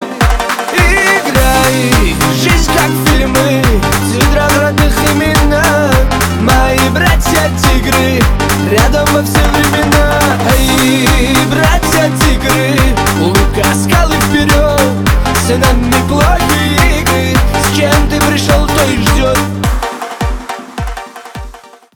поп , кавказские